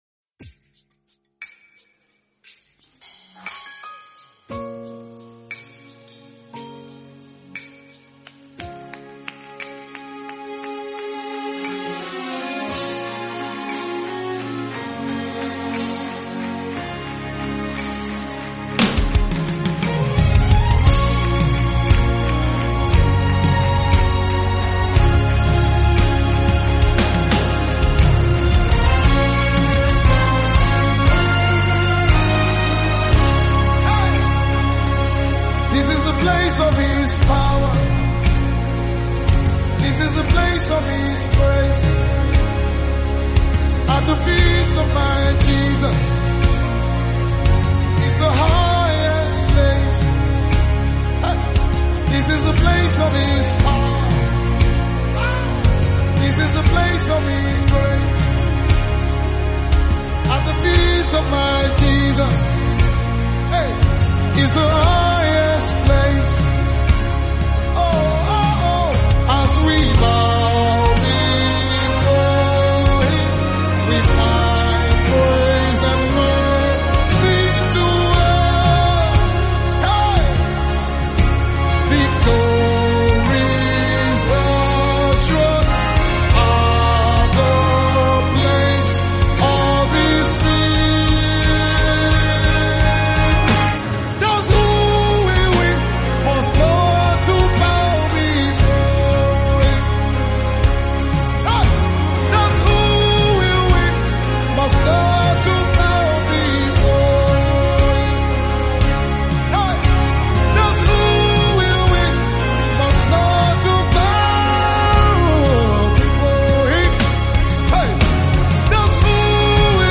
5/14 Genre: Gospel Year of Release